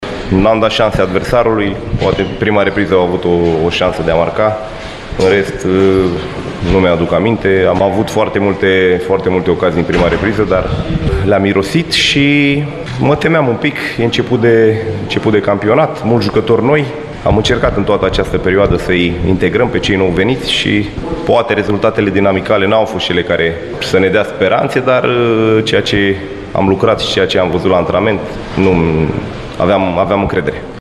În tabăra piteșteană, antrenorul Andrei Prepeliță a arătat o bucurie echilibrată după un meci bun, în totală contradicție cu rezultatele din perioada de pregătire: